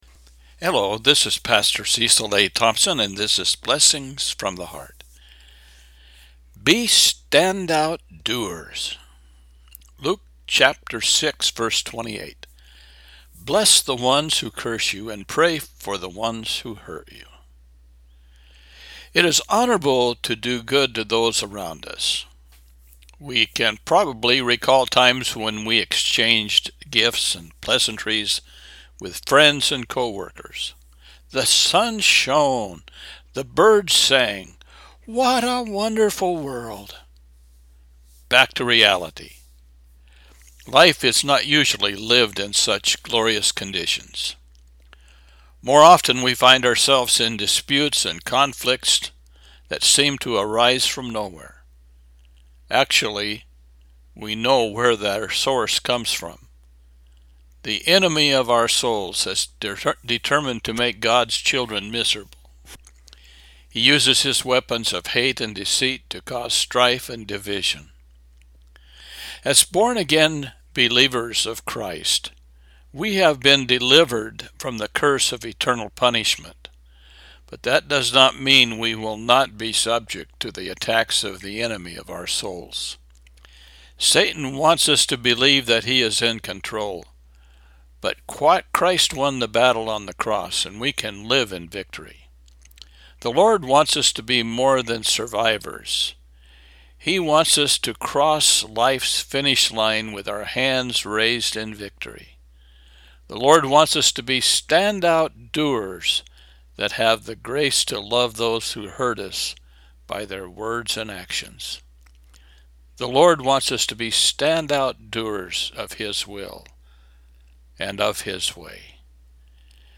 DRAW ME NEARER! – Luke 15:19-20 Devotional